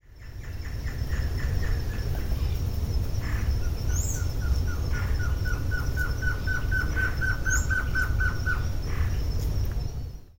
Blue-crowned Trogon (Trogon curucui)
También se escucha vocalizar al Chororo (Taraba major)
Sex: Male
Life Stage: Adult
Detailed location: Campo cercano a la Eco-via Luque San Bernardino
Certainty: Observed, Recorded vocal
Trogon-curucui.mp3